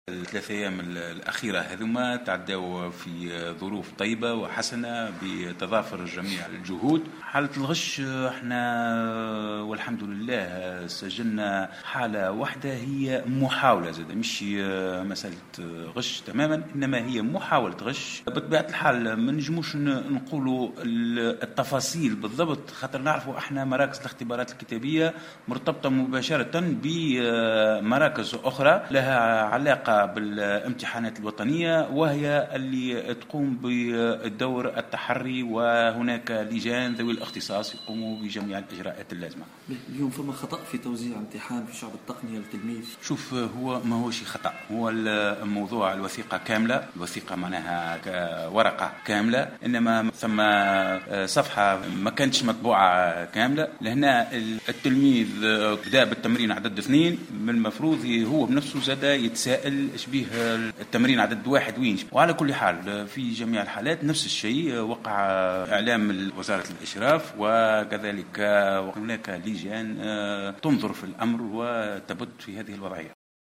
تم خلال الأيام الثلاث الأولى من مناظرة الباكاوريا في ولاية المنستير تسجيل محاولة غش وتوزيع لوثيقة امتحان منقوصة عن طريق الخطأ وفق ما صرّح به المندوب الجهوي للتربية فتحي السلامي لمراسل الجوهرة أف أم في الجهة.